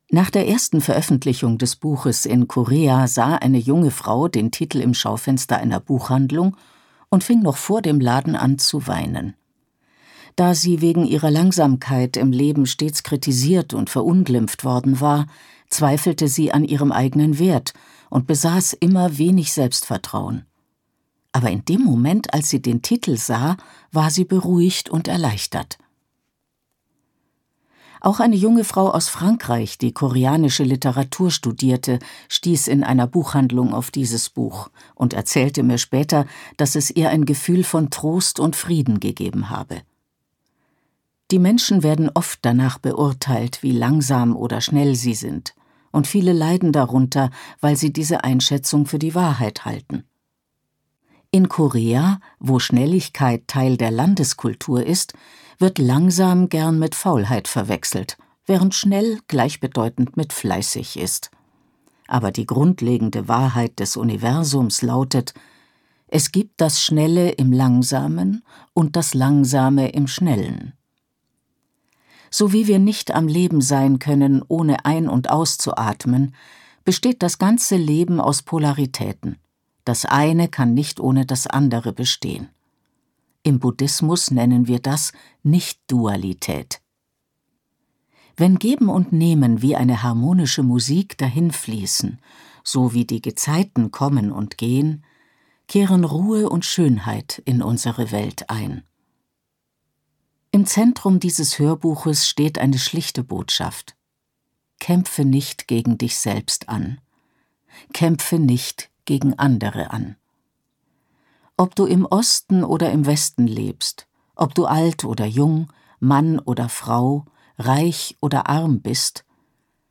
Die Schnecke ist langsam, aber nie zu spät - Jung-mok | argon hörbuch
Gekürzt Autorisierte, d.h. von Autor:innen und / oder Verlagen freigegebene, bearbeitete Fassung.